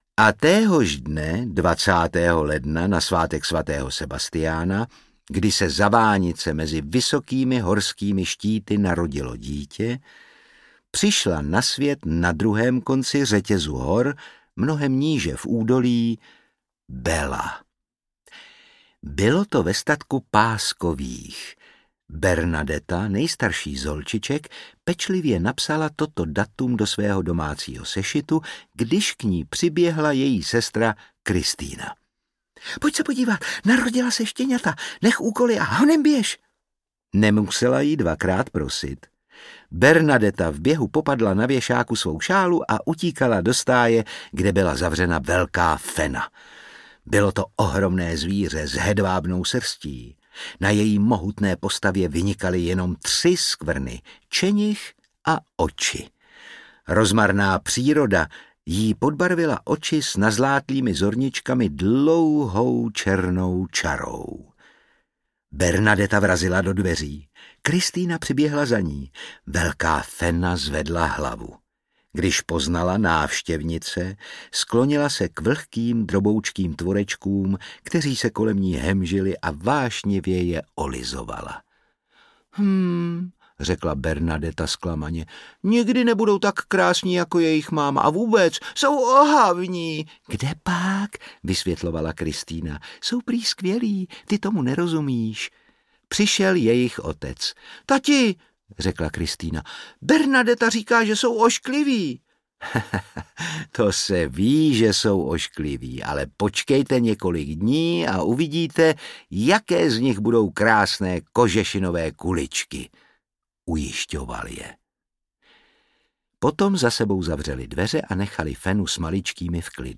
Bella a Sebastián: Útulek na Velkém Baou audiokniha
Ukázka z knihy
Čte Otakar Brousek.
Vyrobilo studio Soundguru.
• InterpretOtakar Brousek ml.